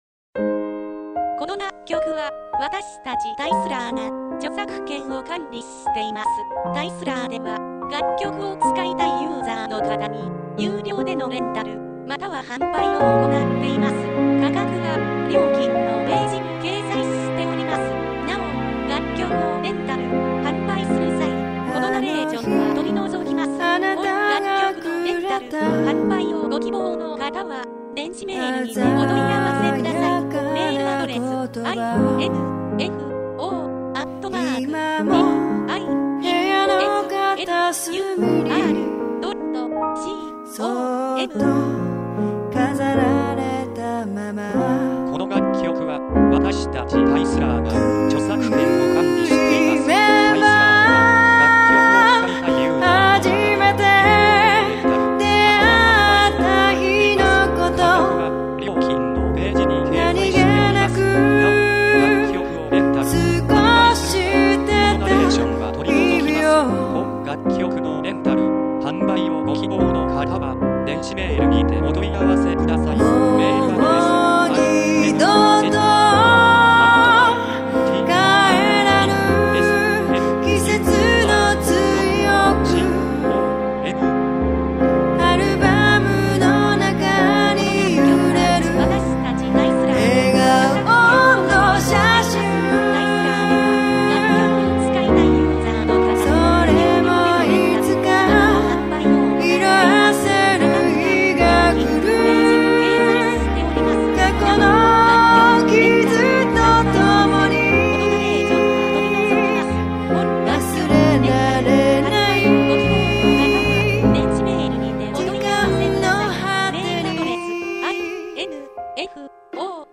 ◆バラード系ボーカル曲